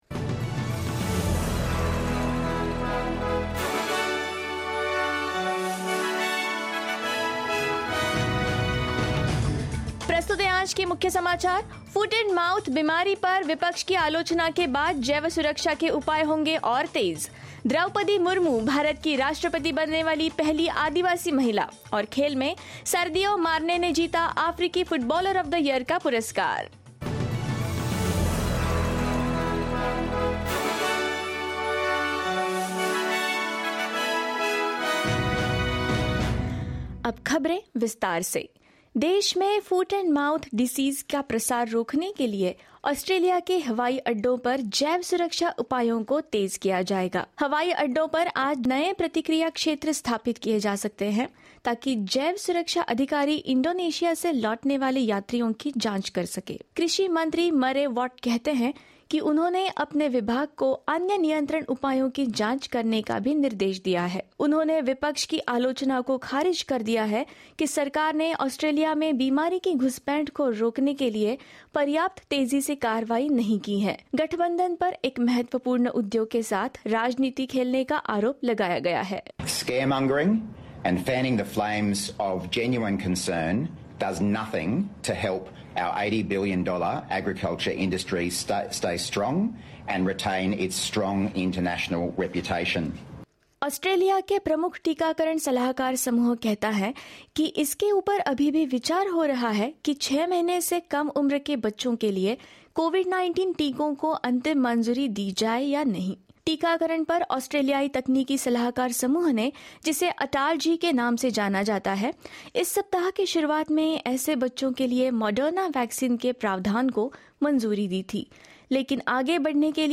In this latest SBS Hindi bulletin: New biosecurity measures to be followed on international airports in Australia; Draupadi Murmu becomes the first tribal President of India; Sadio Mane claims the African Footballer of the Year award and more.